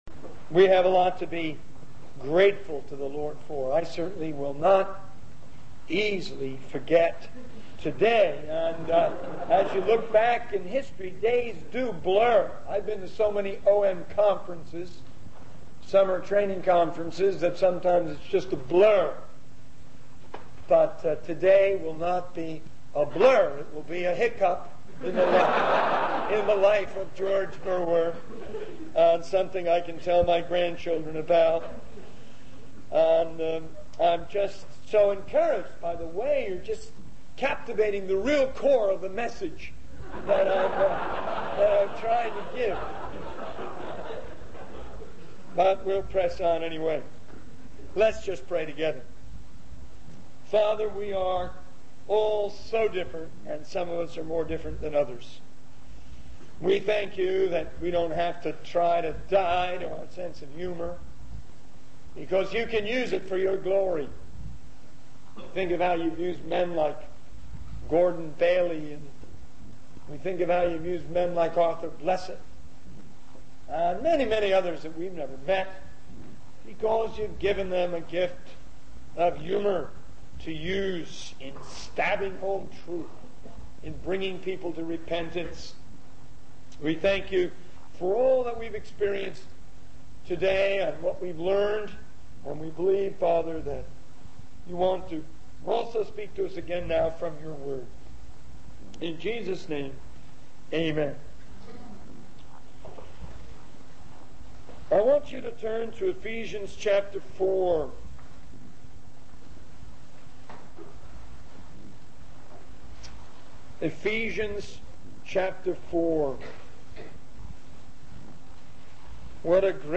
In this sermon, the speaker discusses the importance of maintaining spiritual balance as Christians. He emphasizes that balance does not mean compromise or wishy-washiness, but rather a harmony of truth and spirituality.